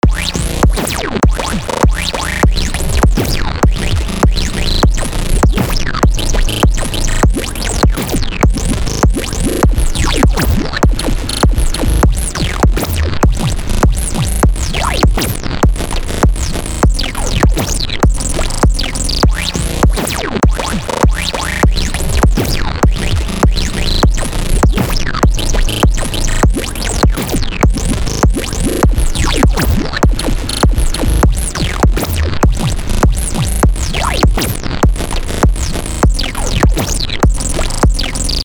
how can i create a sin lfo in scriptnode TT i found a crazy way to make laser sounds today with eqs but i cant figure out how to make a sin lfo please help TT (i made the plugin in patcher with my logic i just want to convert that idea into a plugin)
i got above sound without the kick from that plugin i made in patcher